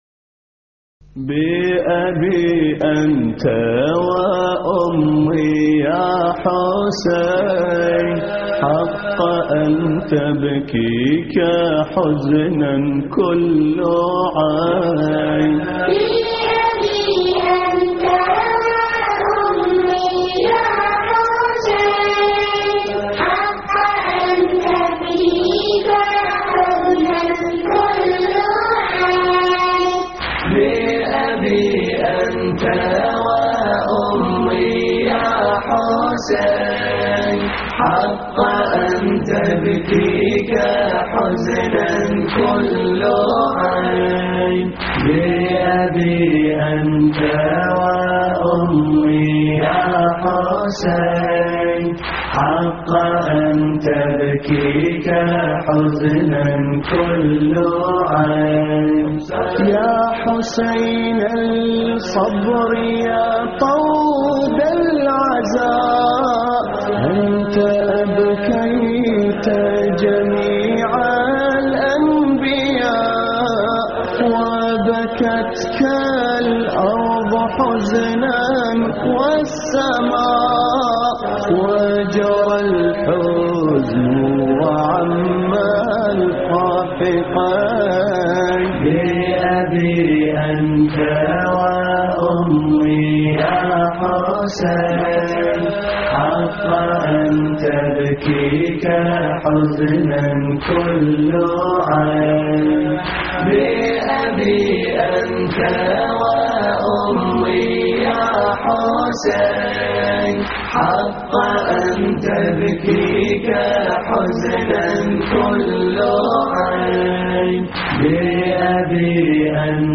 استديو
الرادود